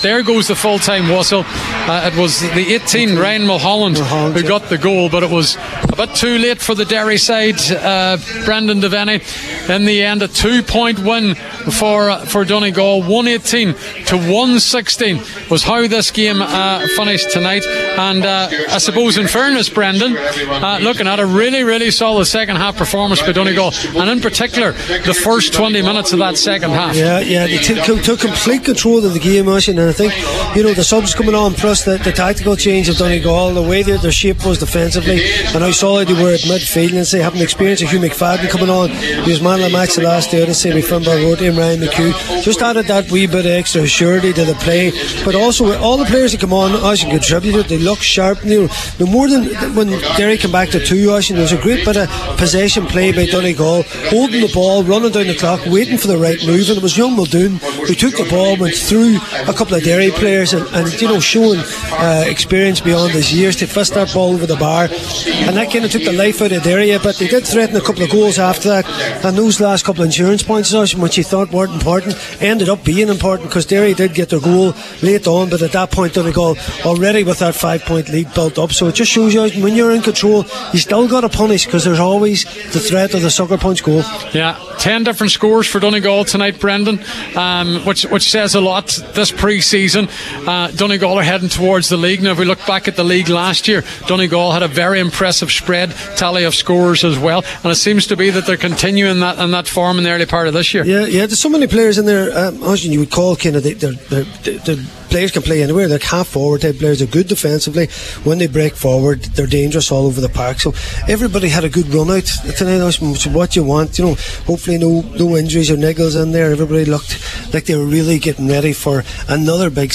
live at full time in Derry for Highland Radio Sport…